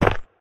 step-4.ogg.mp3